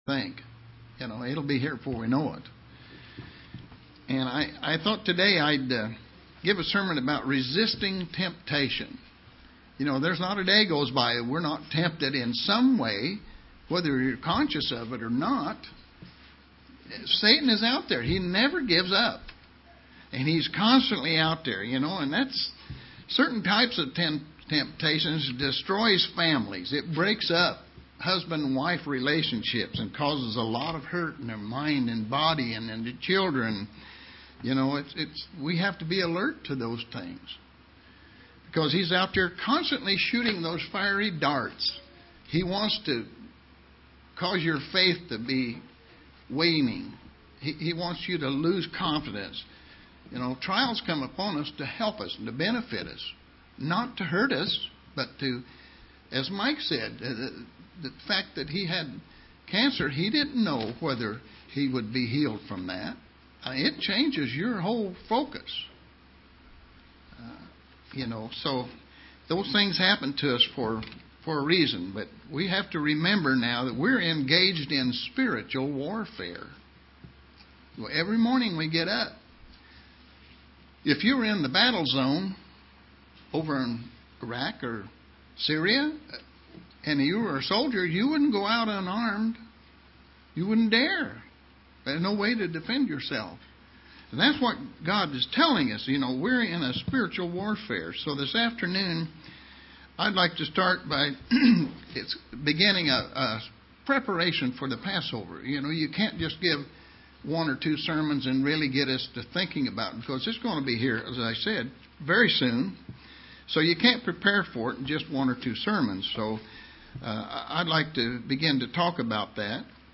Print Resisting temptation and preparing for the Passover UCG Sermon Studying the bible?